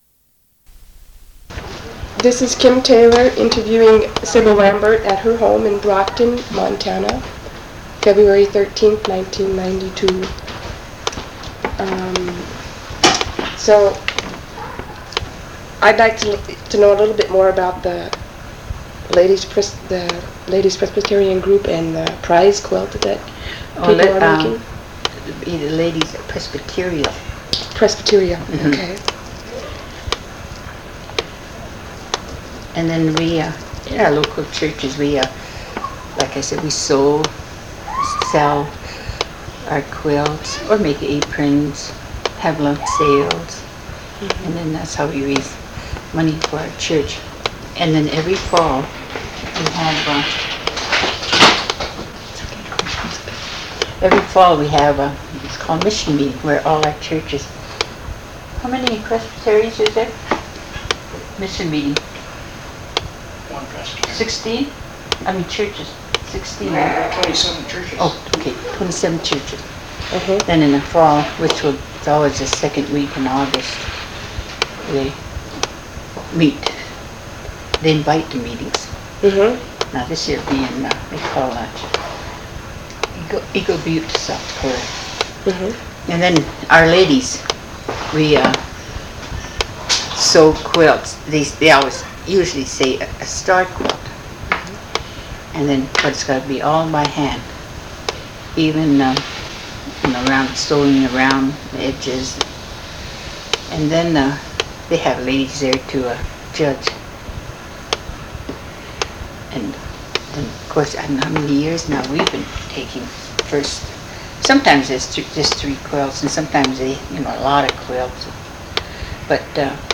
Document Type Oral History